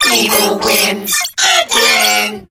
8bitvirus_kill_vo_04.ogg